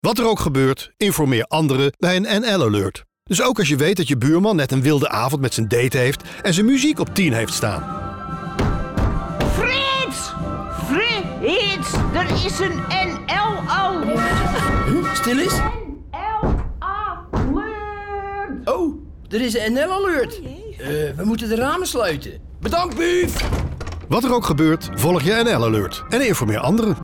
Er zijn twee radiospots voor de campagne informeer anderen:
NL-Alert informeer anderen | Radiospot Bolero